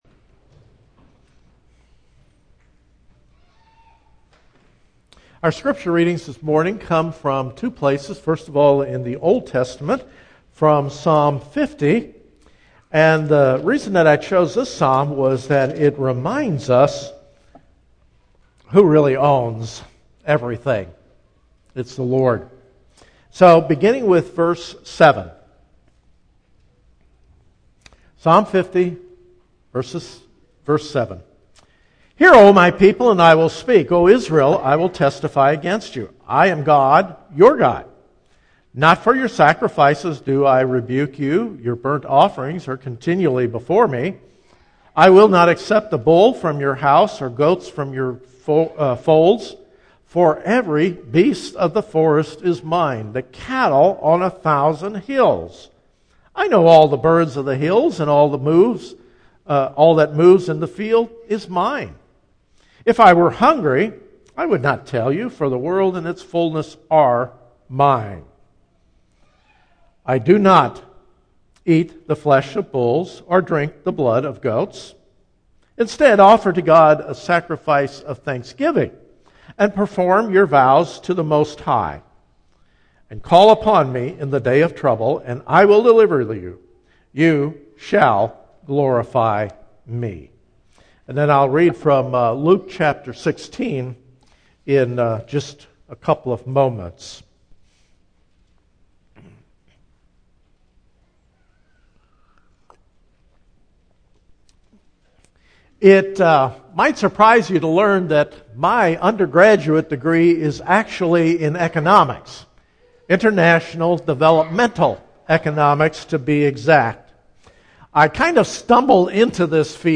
Series: Single Sermons Passage: Psalm 50:7-15, Luke 16:1-13 Service Type: Morning « On Trial